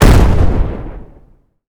explosion_large_01.wav